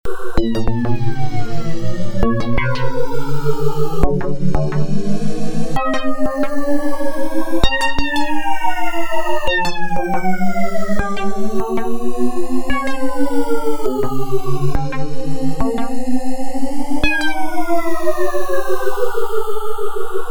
So etwas meine ich mit multi portamento: Anhänge FM8TestMultiPorta.mp3 FM8TestMultiPorta.mp3 445,8 KB · Aufrufe: 204